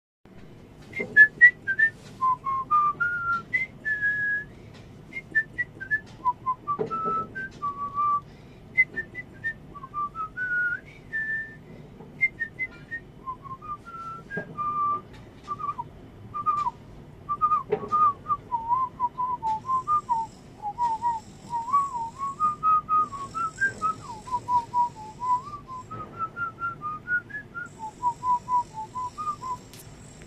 brzmial mniej wiecej tak jak to odgwizdalem w zaloczniku